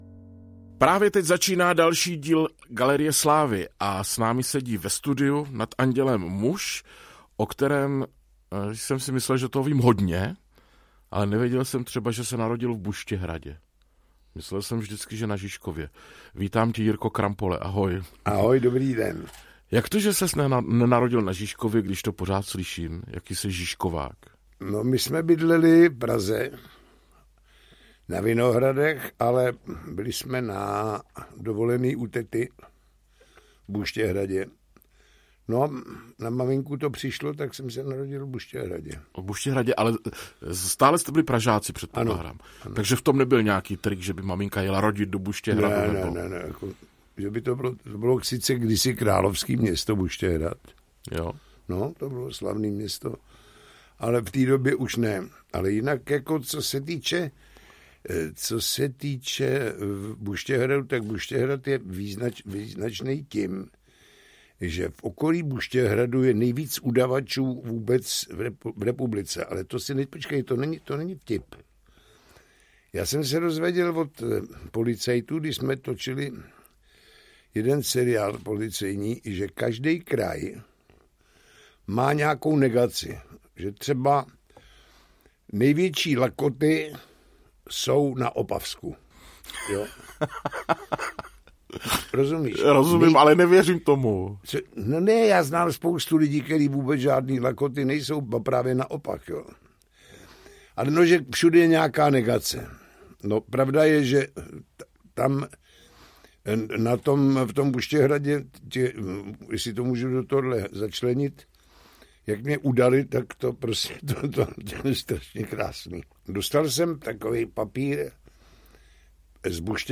Ukázka z knihy
Ani tentokrát nechybí Galerii slávy humor, nadsázka a kapky životní moudrosti.
Každý díl GALERIE SLÁVY je de facto mluveným životopisem konkrétní osobnosti.